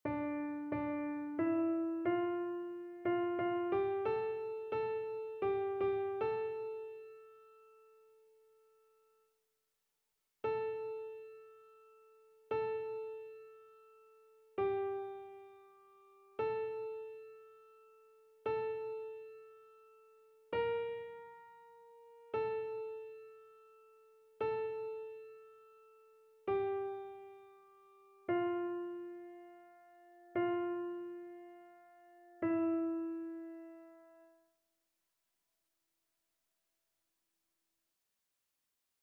Chœur
annee-b-temps-pascal-3e-dimanche-psaume-4-soprano.mp3